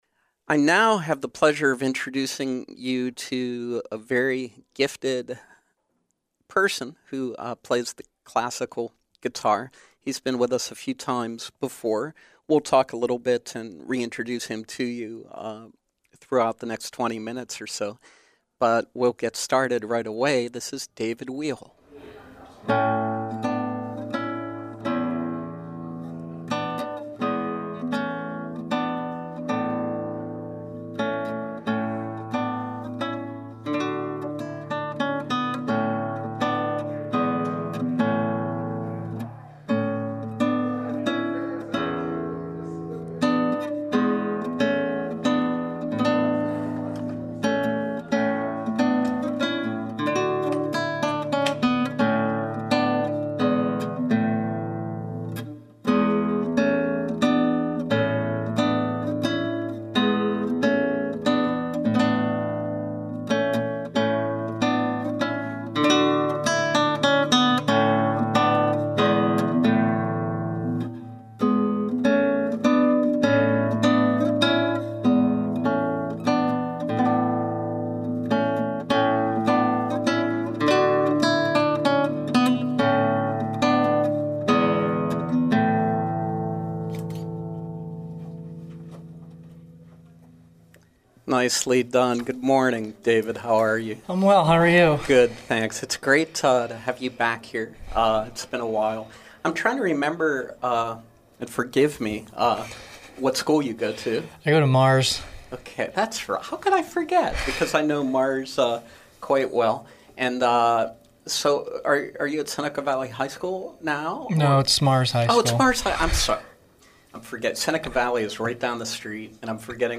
classical guitarist